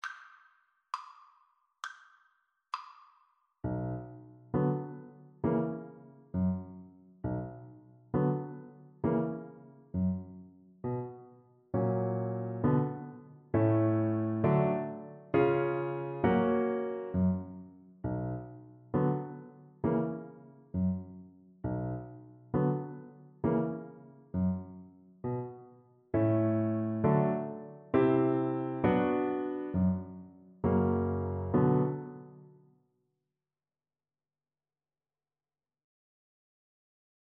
Moderato
Classical (View more Classical Viola Music)